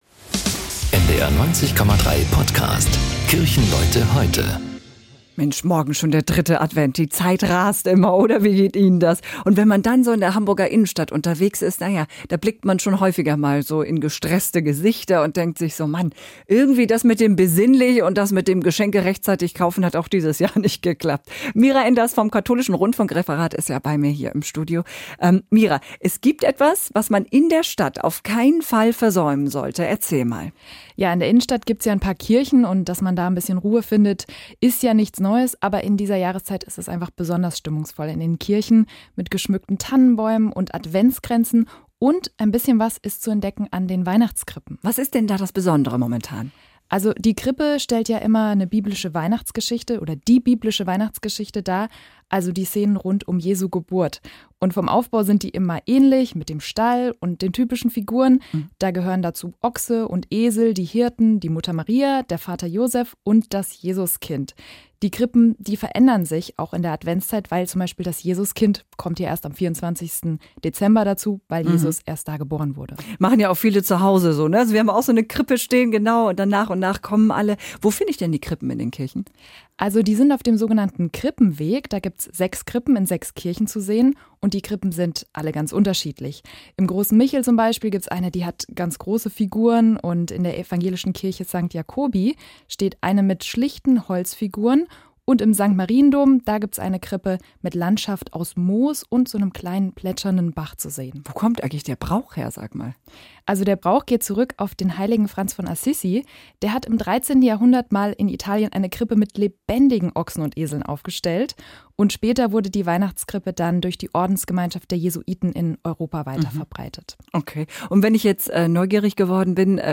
Hamburger Pastorinnen und Pastoren und andere Kirchenleute erzählen